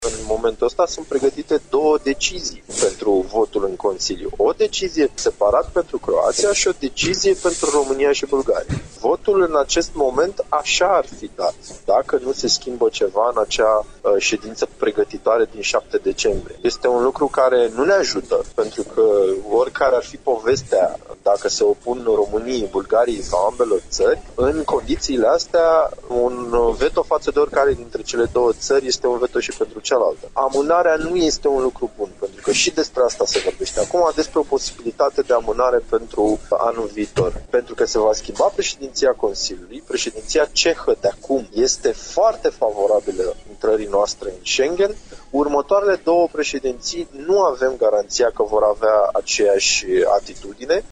Într-o intervenție pentru Radio România Iași, europarlamentarul Vlad Gheorghe a vorbit interesul țării noastre de a obține un răspuns pozitiv în această lună, pe mandatul președinției Republicii Cehe a Consiliului Uniunii Europene: În momentul ăsta sunt pregătite două decizii pentru votul în consiliu, o decizie separat pentru Croația și o decizie pentru România și Bulgaria.